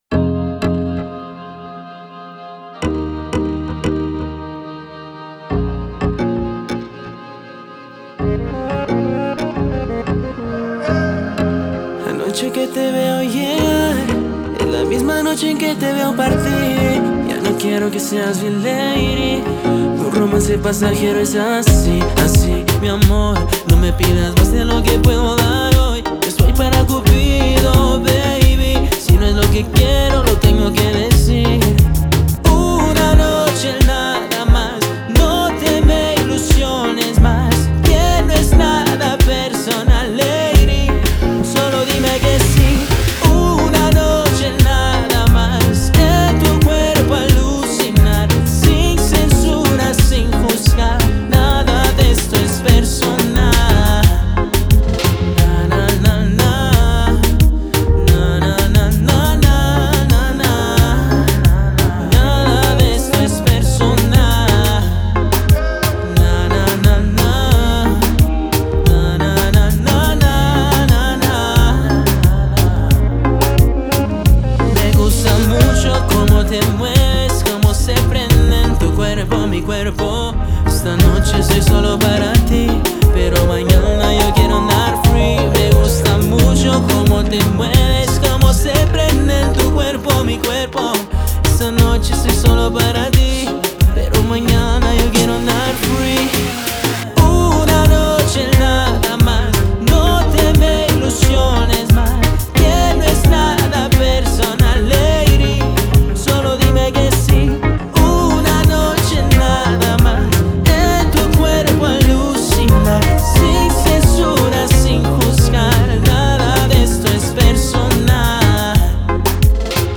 Producción musical, Composición, Fusión, Pop Latino, Orgánico, Público target, Estética